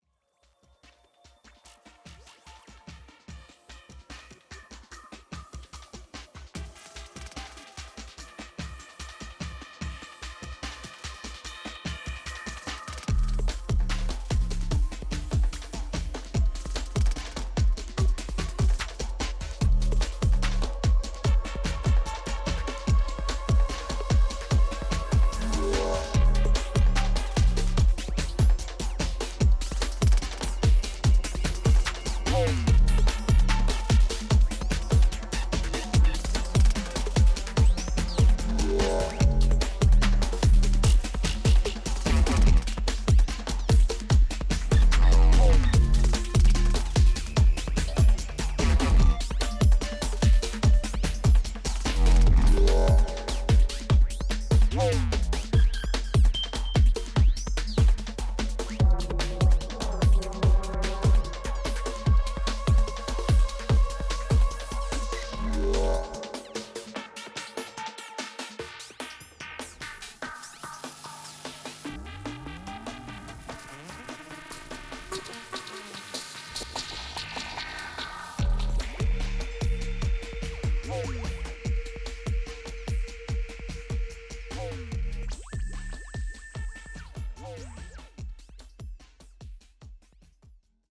アグレッシヴなデンボウ的ドラムが特徴の
オーガニックなテクスチャーを軸に卓越したグルーヴ感とサイケデリックな音響志向が見事に合致したテクノの新感覚を提示。